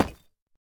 Minecraft Version Minecraft Version latest Latest Release | Latest Snapshot latest / assets / minecraft / sounds / block / deepslate_bricks / place5.ogg Compare With Compare With Latest Release | Latest Snapshot